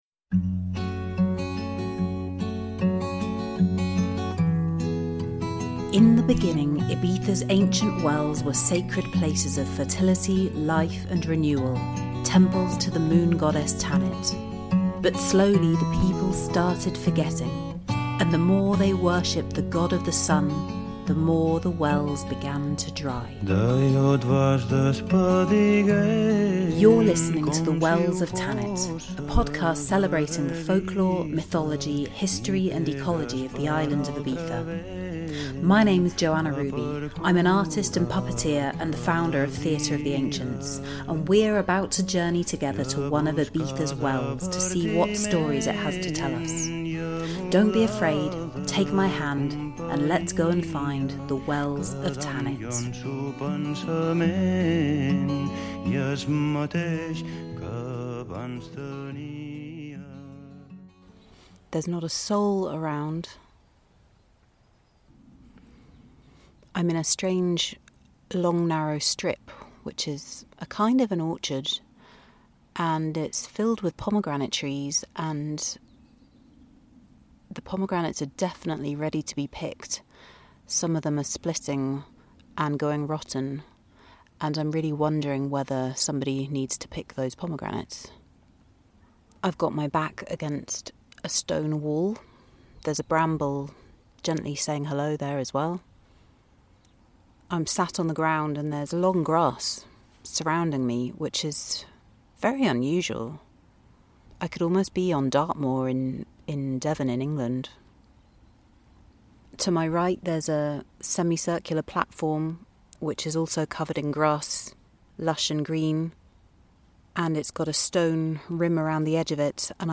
It’s the eve of Tots Sants , or Samhain, and this episode comes from the depths of a pomegranate grove in the lost depths of the Benimussa valley, in the South of Ibiza.